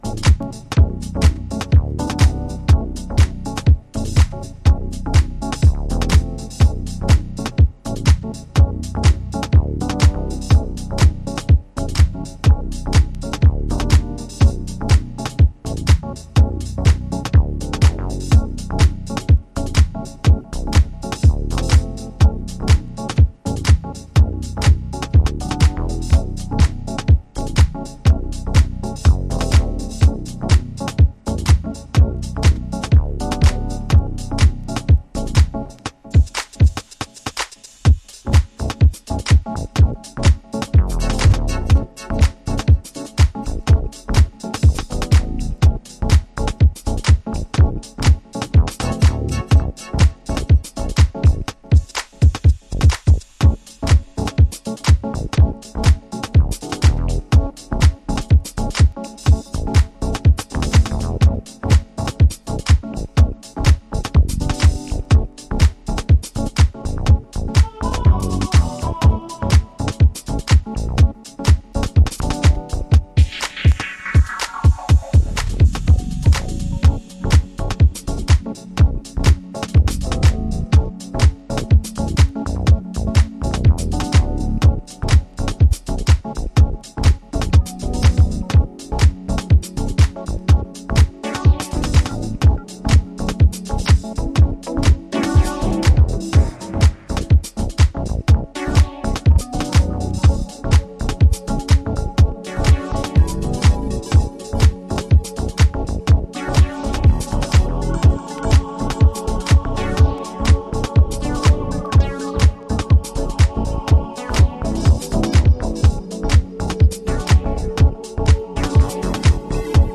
Detroit House / Techno